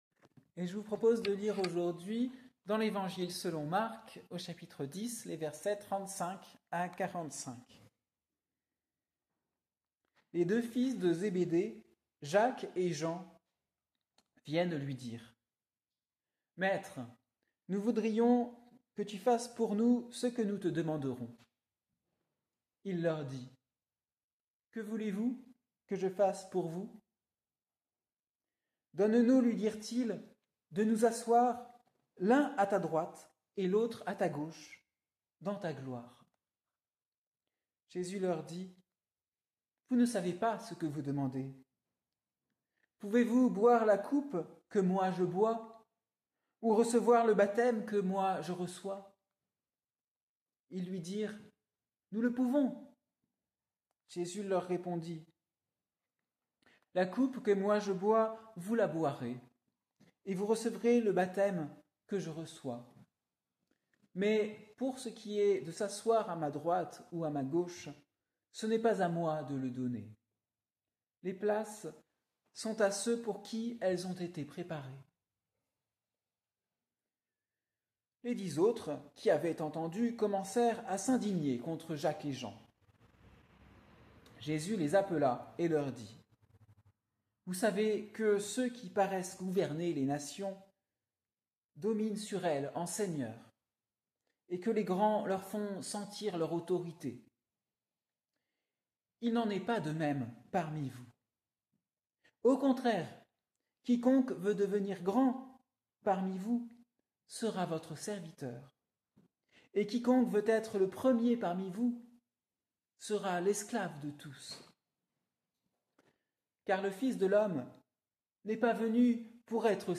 Prédication du dimanche 20 octobre 2024.